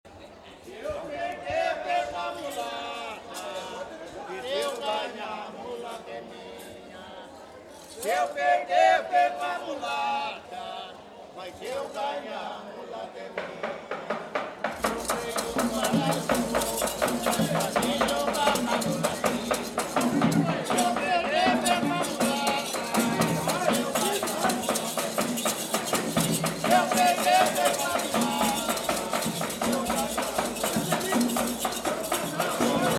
GêneroBatuque de Umbigada
Batuque de Umbigada em Tietê – 24set2000